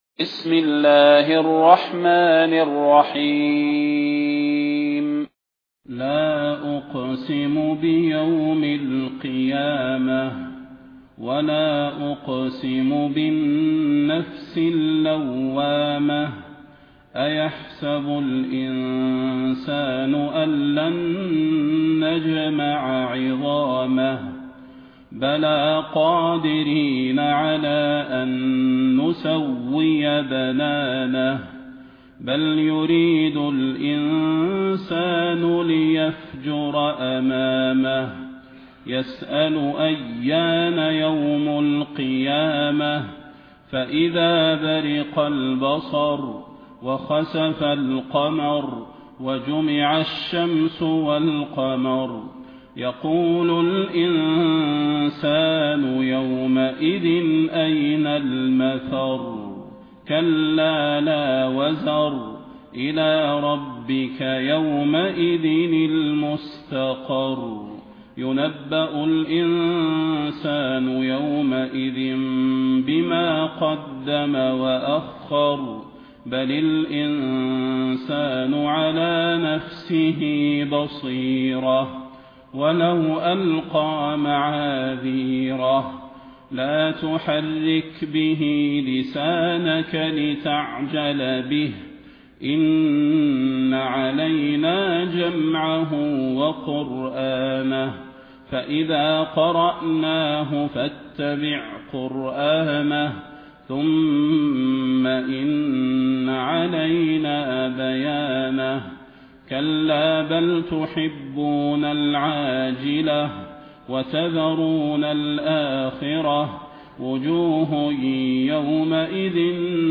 المكان: المسجد النبوي الشيخ: فضيلة الشيخ د. صلاح بن محمد البدير فضيلة الشيخ د. صلاح بن محمد البدير القيامة